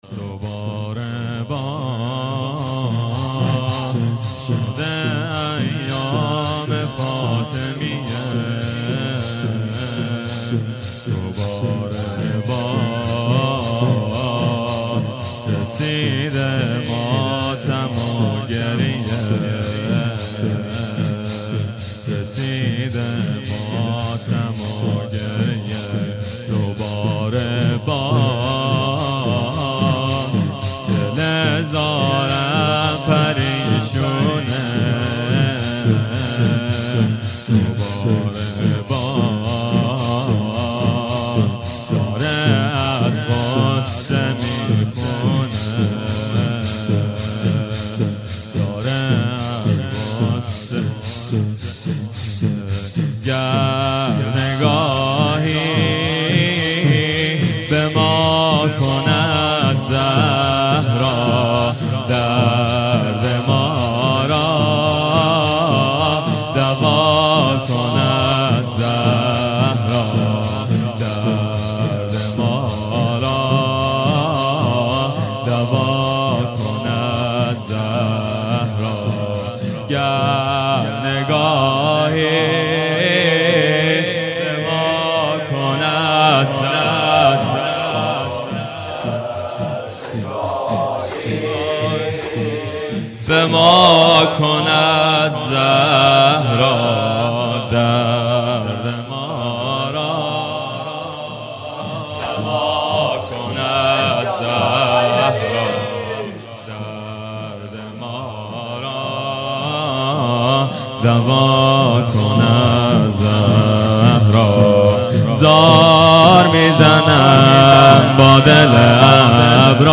مراسم عزاداری شب اول فاطمیه دوم 1393